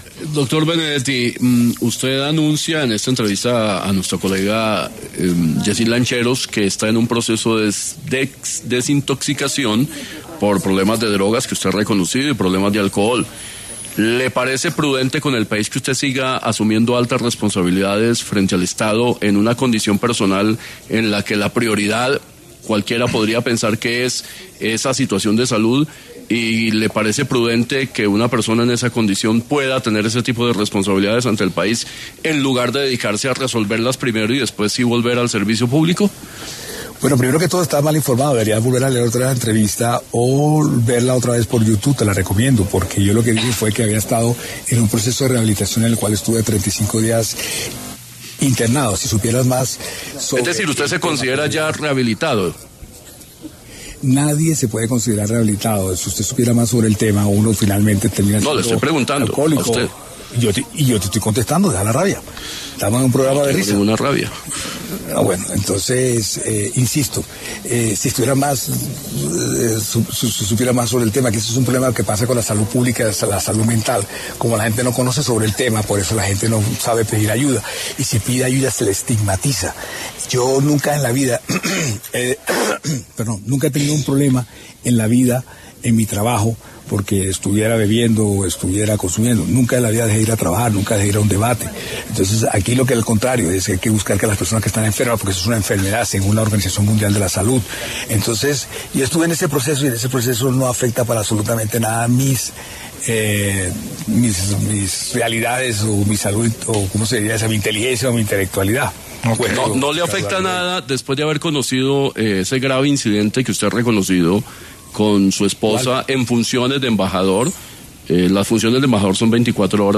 En una reciente entrevista con ‘La Luciérnaga’, Armando Benedetti, el ex embajador de Colombia en Venezuela, hizo declaraciones sobre su lucha con el consumo de las drogas y el alcohol y de como ha sido el proceso con su esposa.